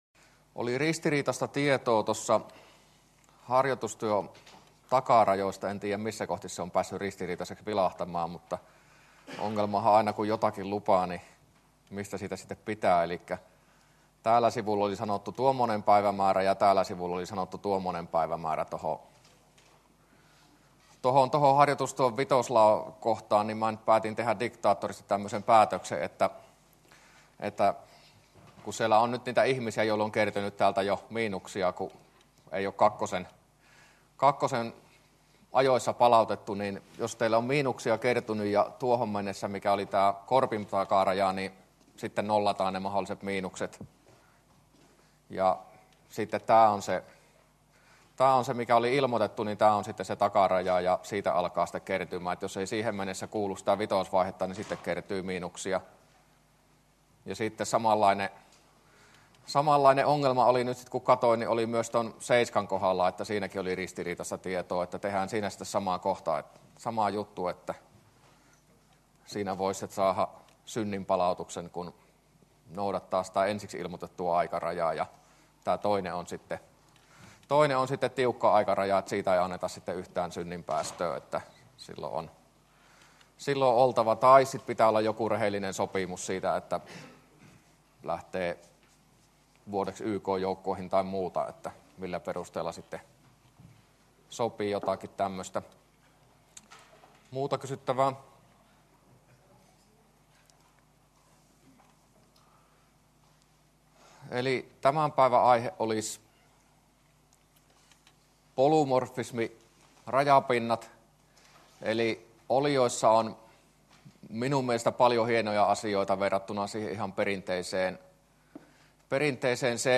luento12a